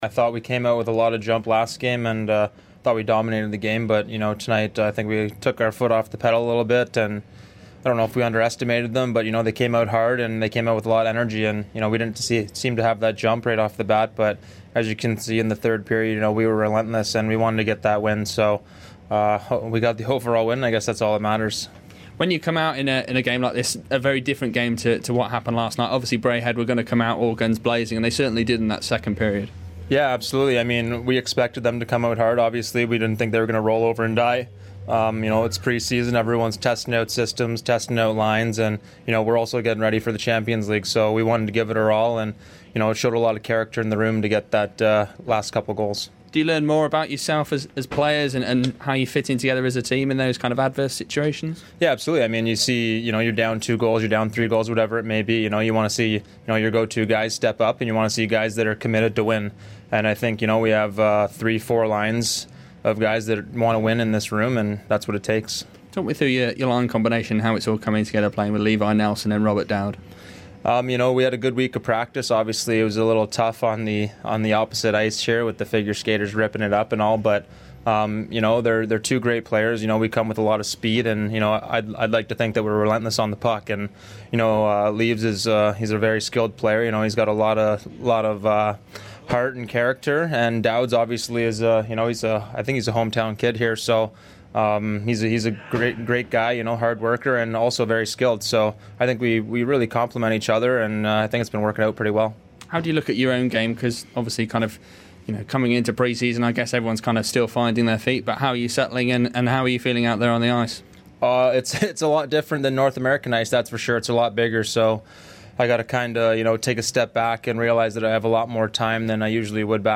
Football Heaven / INTERVIEW